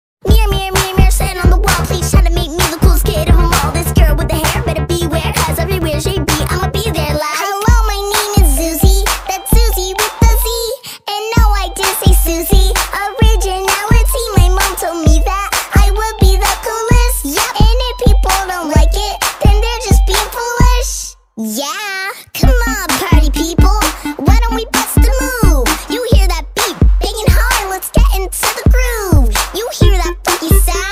catchy, fun pop track